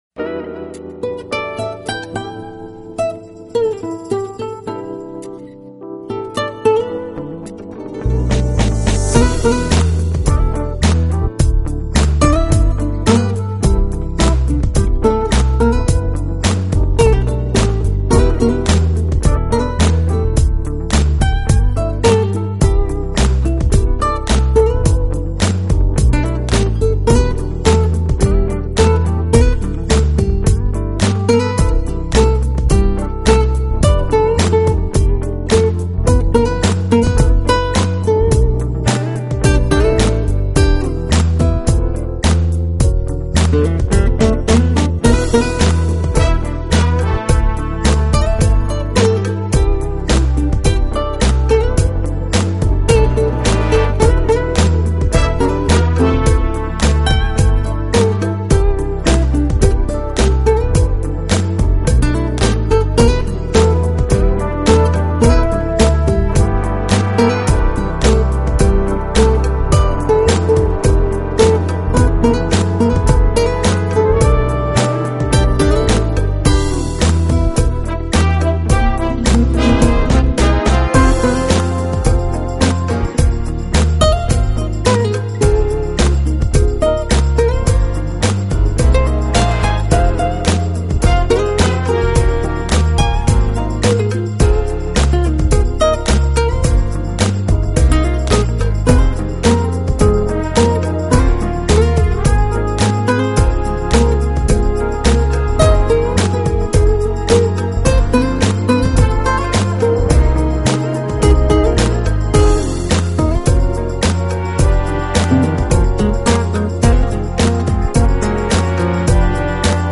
【爵士合辑】